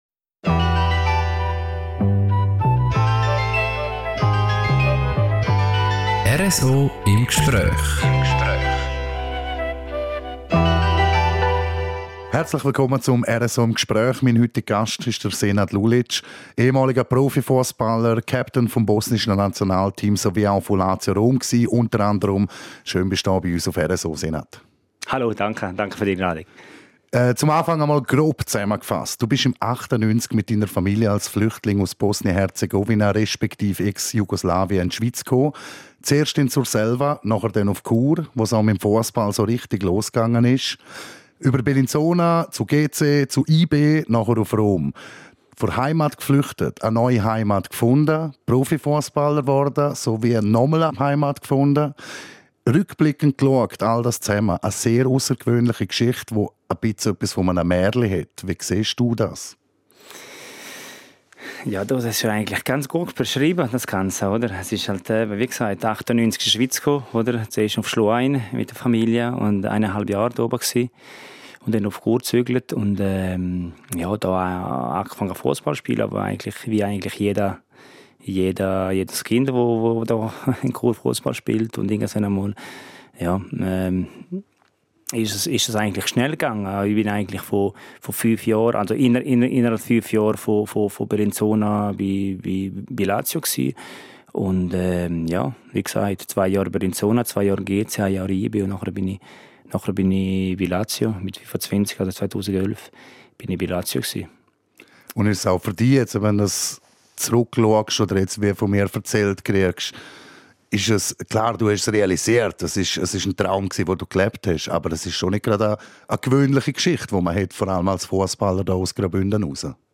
Als Flüchtling zum Fussball-Profi - die Story von Senad Lulic wirkt wie ein Märchen. Wir haben den bodenständigen Ex-Profi zum Talk getroffen.